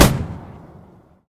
mg-shot-6.ogg